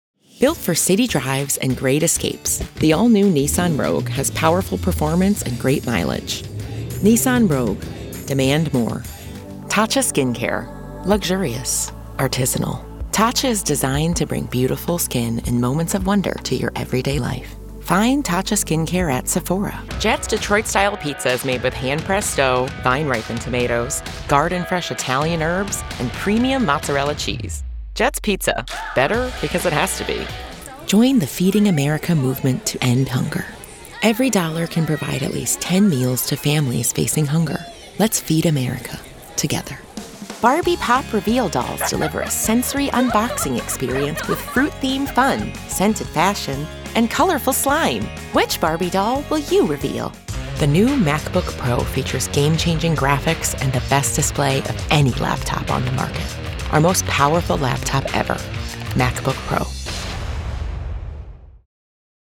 Today, that love of speaking has grown into a voice that is warm, expressive, and effortlessly conversational.
Drawing on years of public speaking and experience presenting on video, along with professionally produced commercial and narration demos, I bring emotional depth, strong pacing, and a clear storytelling instinct to every script.
Commercial Demo